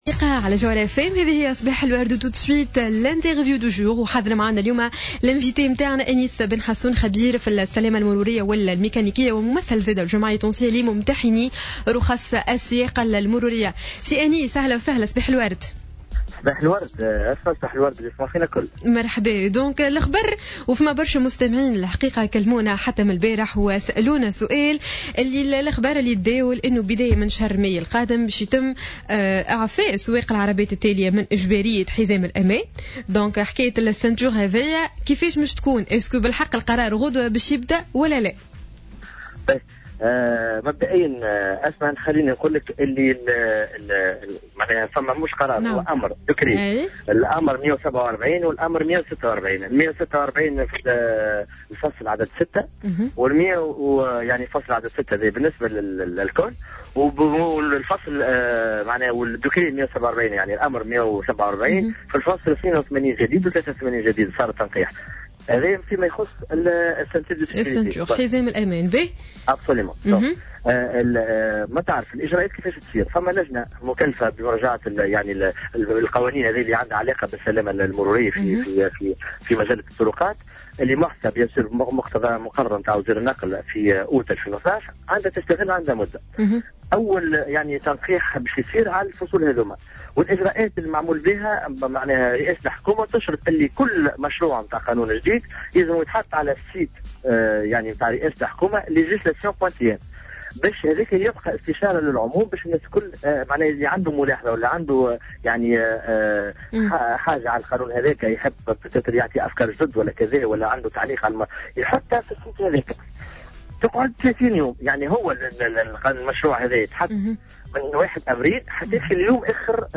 في مداخلة له على جوهرة "اف ام"